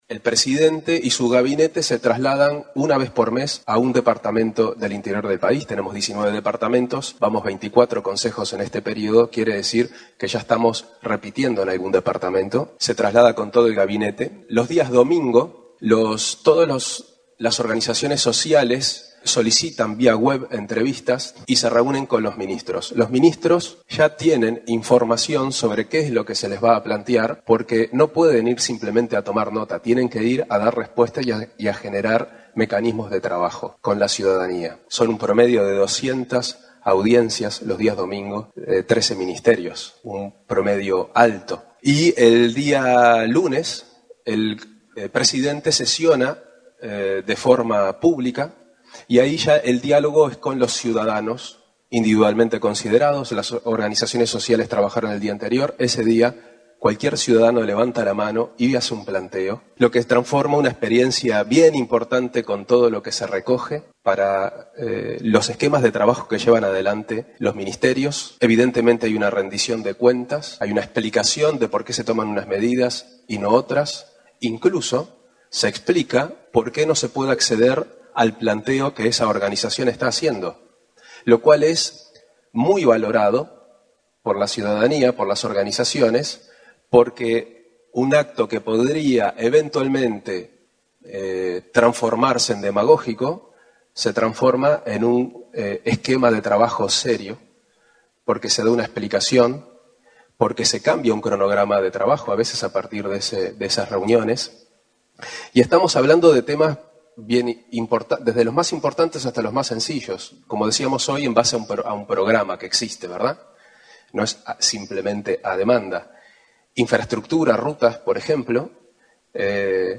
De todos los planteos, el 80 % fueron compromisos asumidos, afirmó el prosecretario de la Presidencia, Juan Andrés Roballo, en un encuentro regional de gobierno abierto, en Argentina.